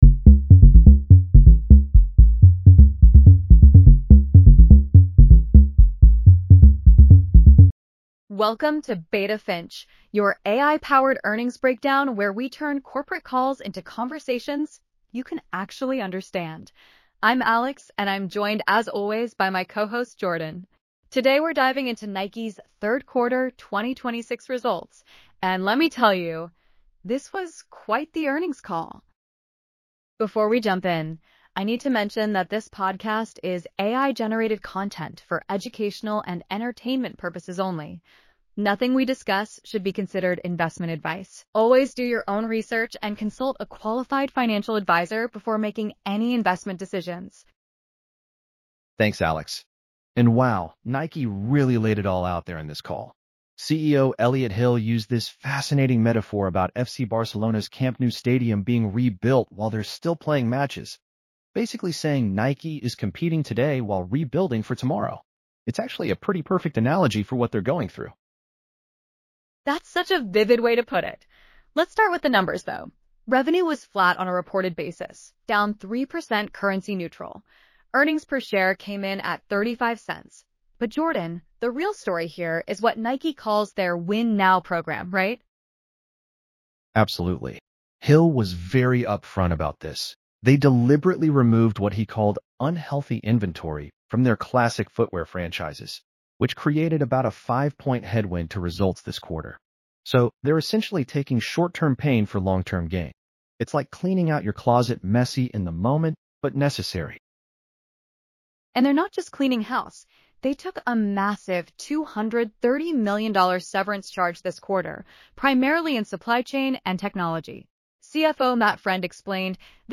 This is AI-generated content for educational purposes only.